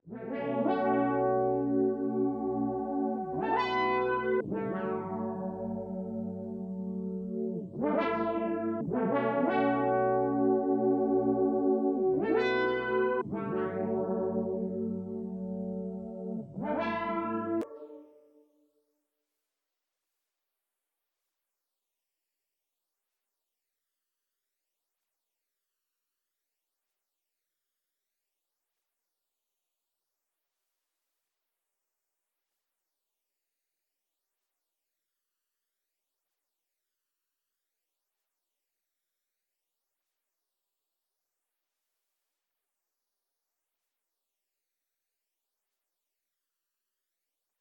(Keys) Cudi_109Bpm.wav